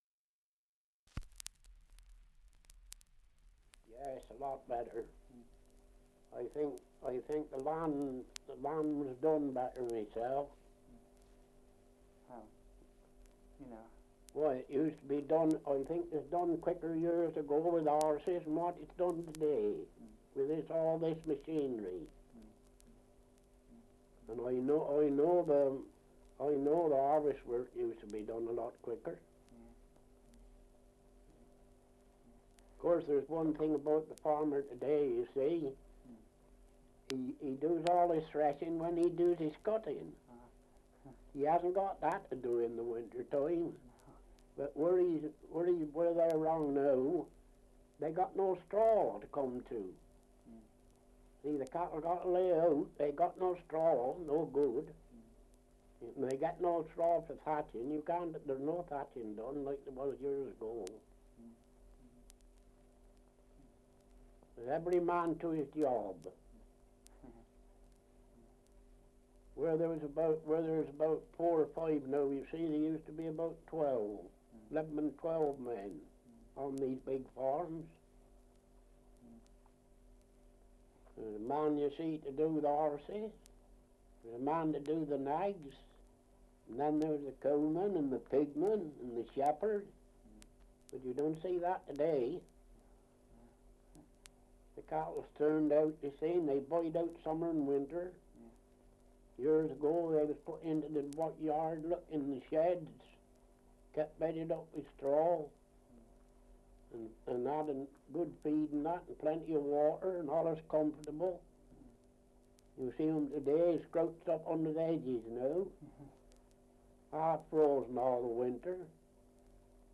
Survey of English Dialects recording in West Ilsley, Berkshire
78 r.p.m., cellulose nitrate on aluminium